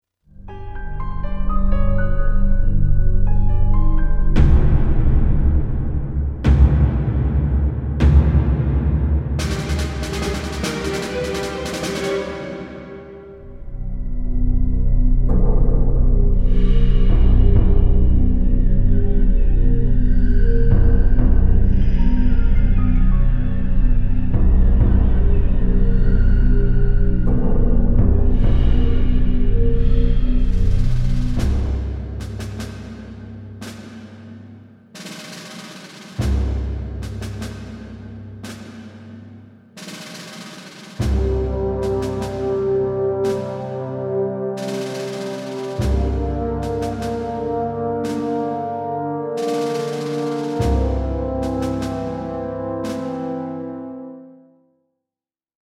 Tense, orchestral